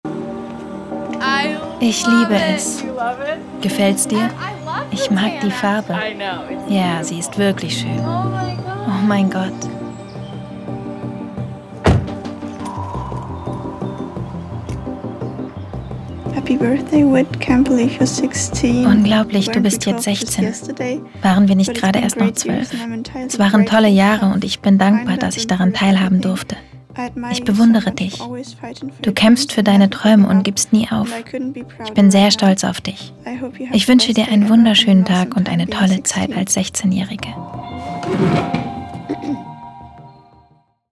hell, fein, zart
Jung (18-30)
Voice Over – NDR „One In A Million“ Dokumentarfilm (2022), Szene 1
Doku